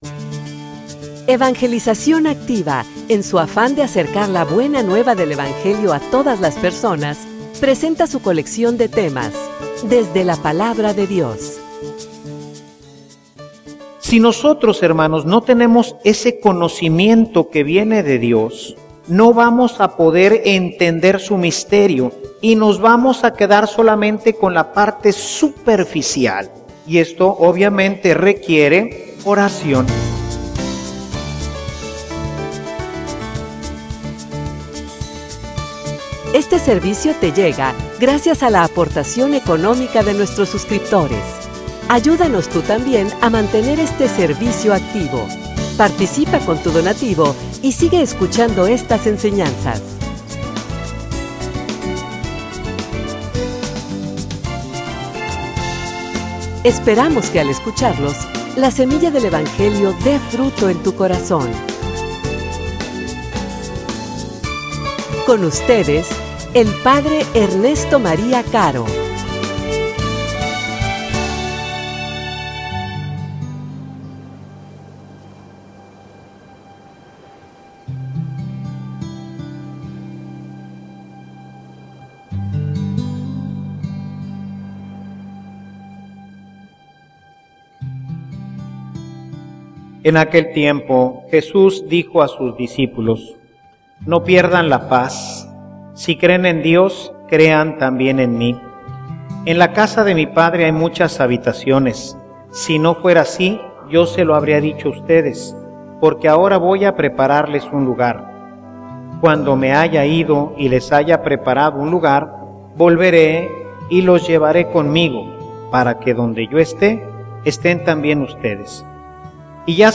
homilia_Encuentro_con_los_ciegos.mp3